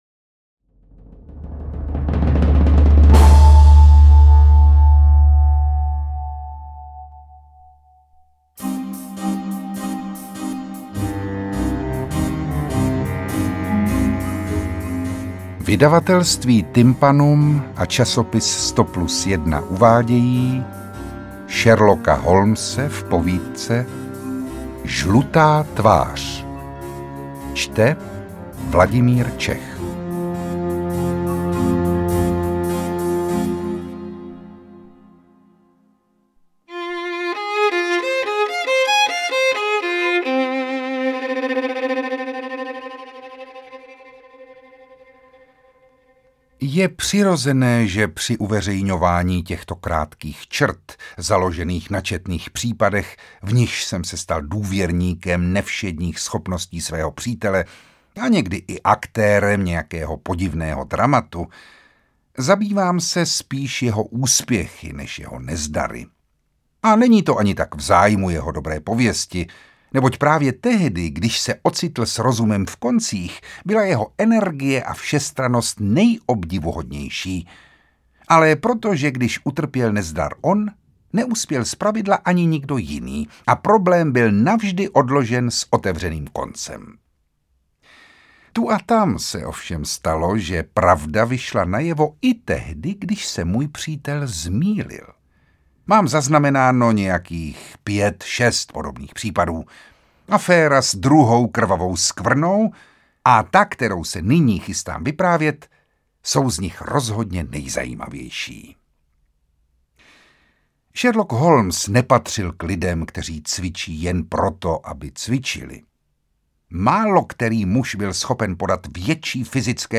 AudioKniha ke stažení, 14 x mp3, délka 59 min., velikost 67,3 MB, česky